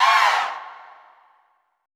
Index of /90_sSampleCDs/Best Service - Extended Classical Choir/Partition I/AHH FALLS
AHH HI FST-R.wav